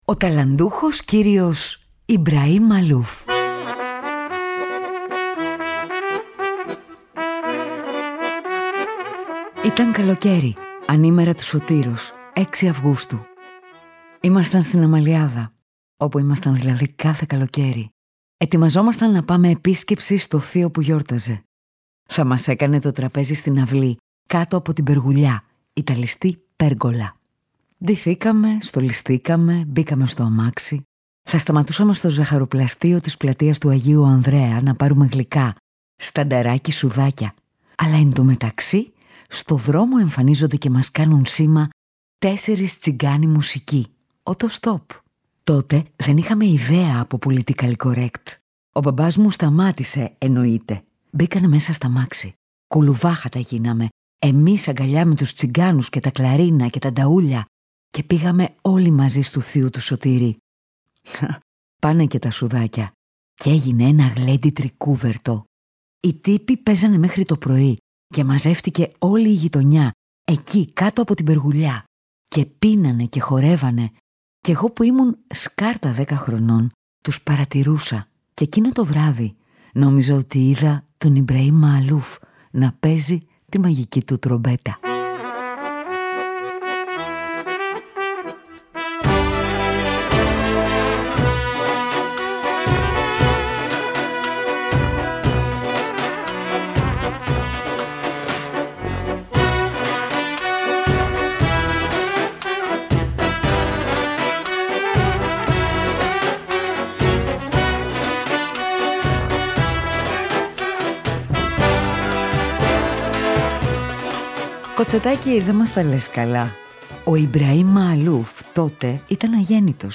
Κι ύστερα, αγάπησε τη τζαζ και μοίρασε τη μουσική του ανάμεσα στην Ανατολή και τη Δύση. Ο ταλαντούχος κύριος Ibrahim Maalouf, ο γαλολιβανέζος σταρ τρομπετίστας, είναι ο προσκεκλημένος αυτού του podcast.